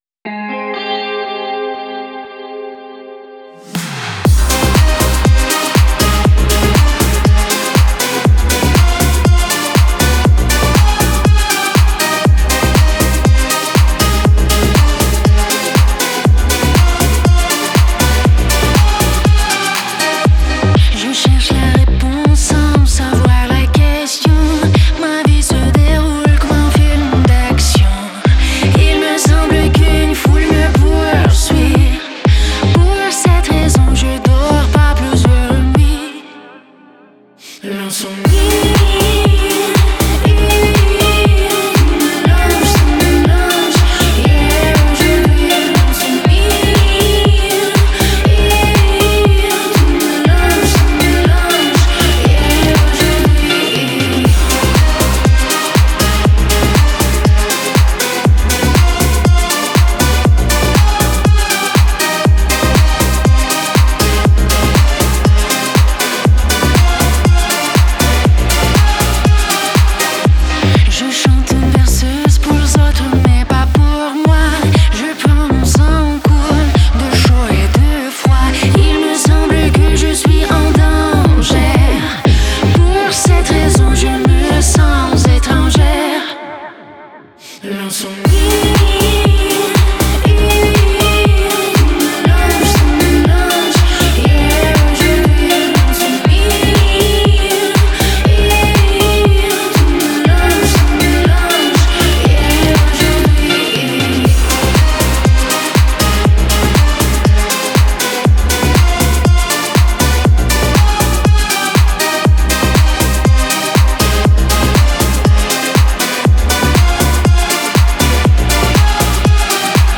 это завораживающая композиция в жанре электронной музыки